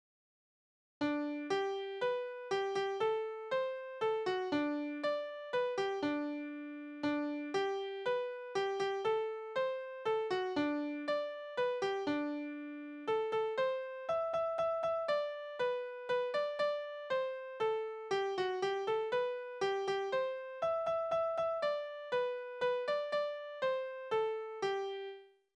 Balladen:
Tonart: G-Dur
Taktart: 3/4
Tonumfang: große None
Besetzung: vokal